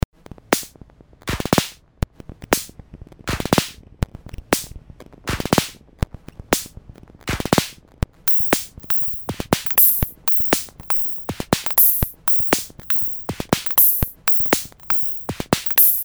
Schaltet man die Kurve von Low auf High um, wird die Bassdrum knackiger oder besser: Es zappt.